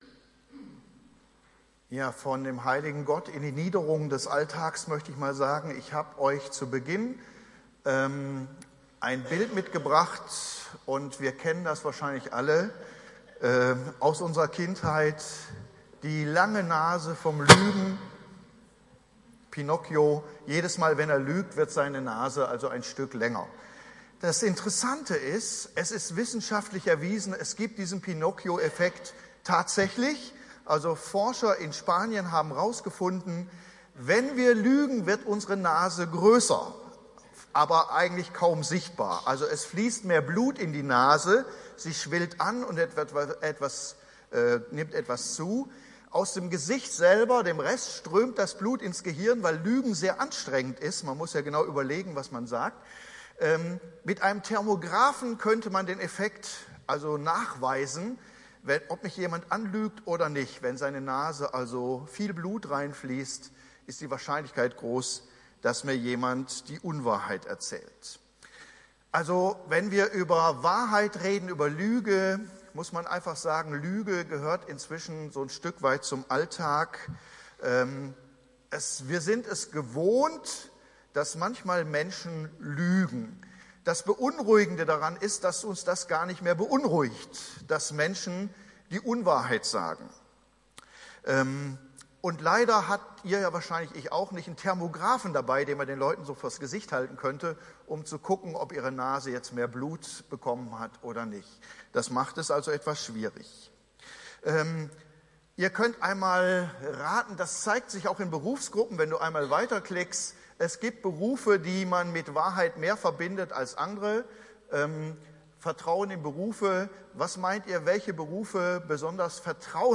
Alles, was darüber hinausgeht, stammt vom Bösen Predigtreihe: Die Bergpredigt